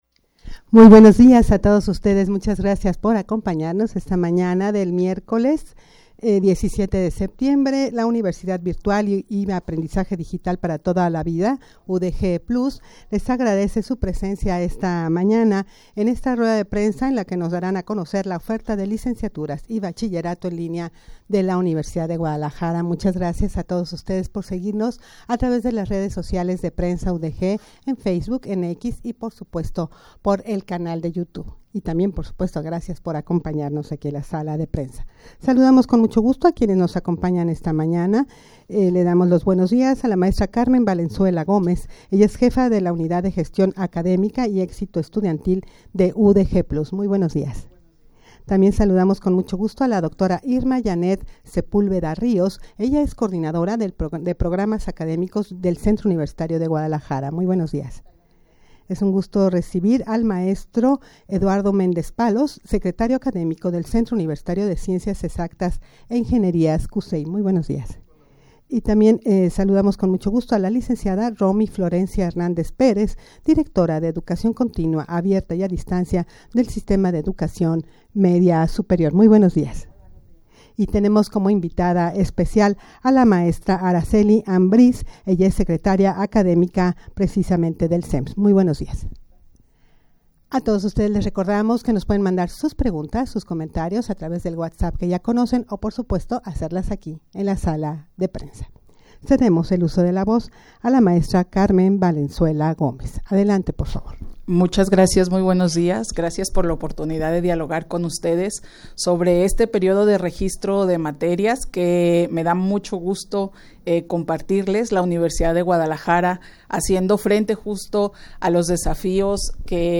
rueda-de-prensa-para-dar-a-conocer-la-oferta-de-licenciaturas-y-bachillerato-en-linea-de-la-udeg.mp3